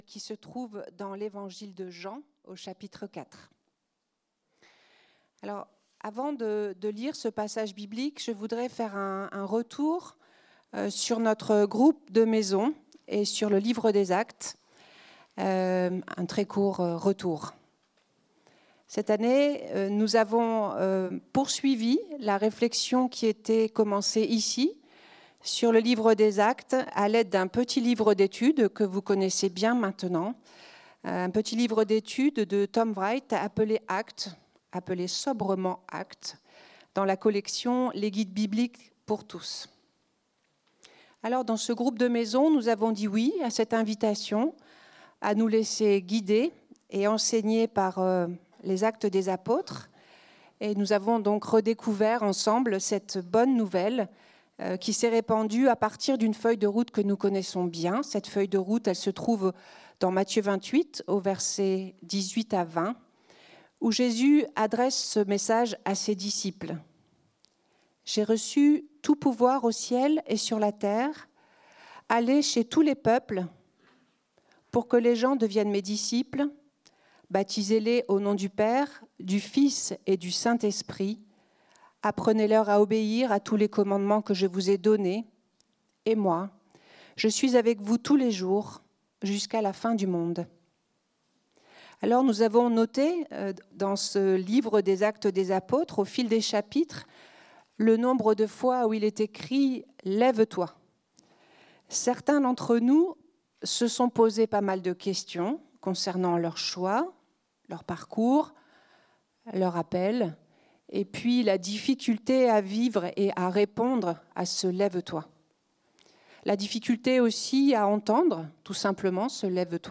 Culte du dimanche 15 juin 2025, prédication